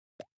plop.mp3